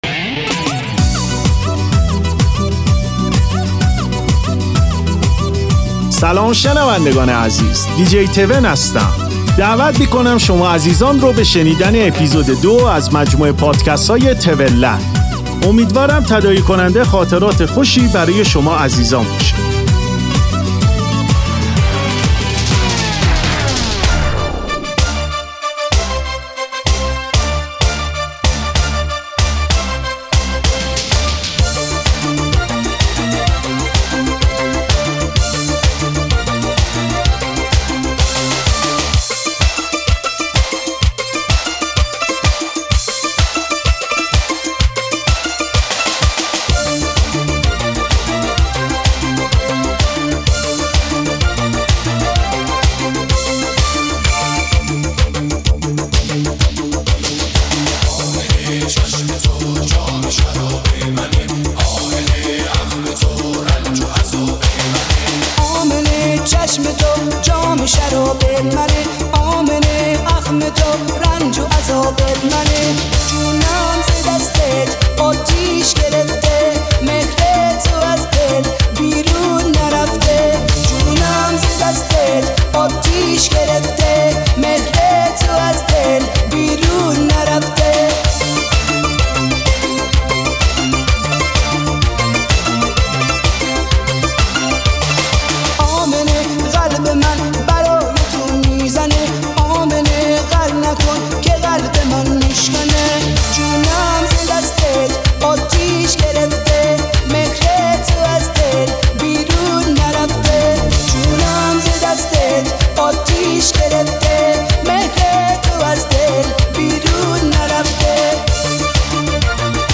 آهنگ شاد
ریمیکس بندری ، ریمیکس شاد